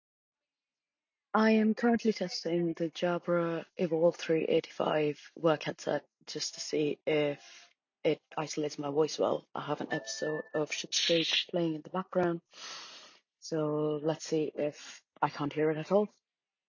I had to hear it for myself, so I recorded myself speaking with the TV playing in the background. In the clip above, you can’t hear the characters from Schitt’s Creek speaking, and my voice is the only one that can be heard, which is amazing.
Jabra Evolve3 85 — Ambient noise.mp3